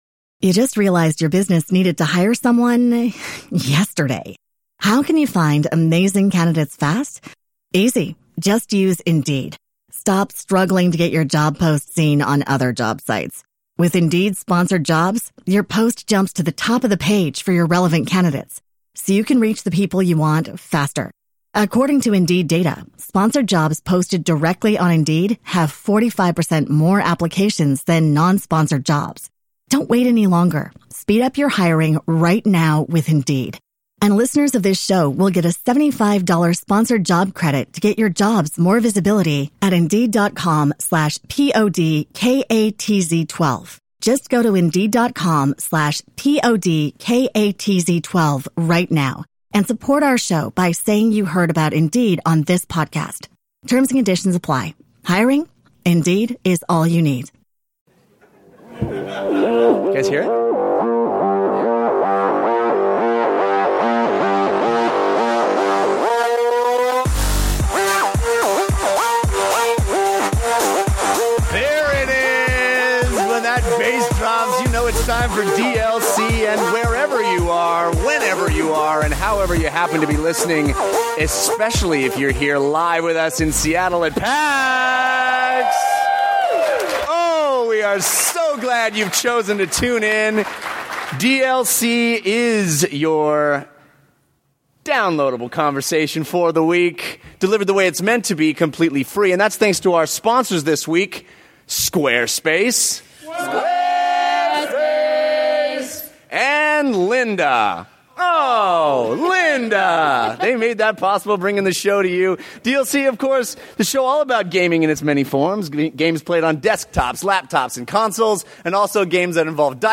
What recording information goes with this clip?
This special episode was recorded in front of a LIVE audience at PAX 2015!